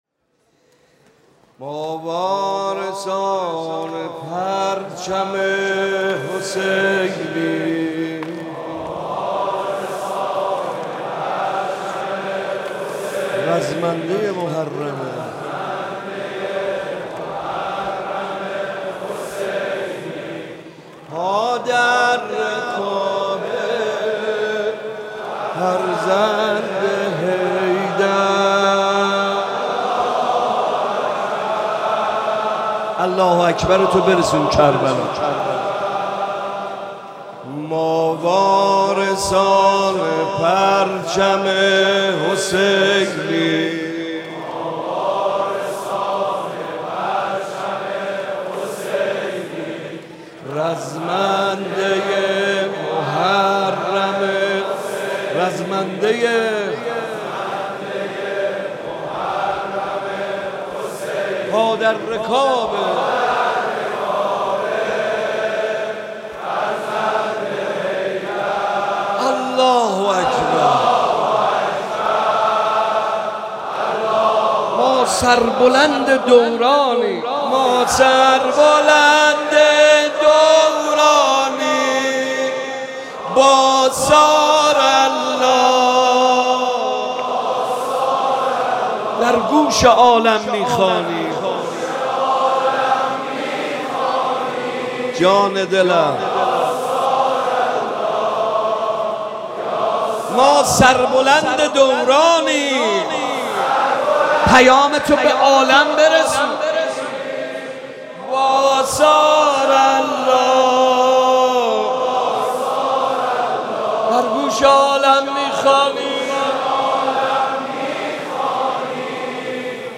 محفل عزاداری شب دهم محرم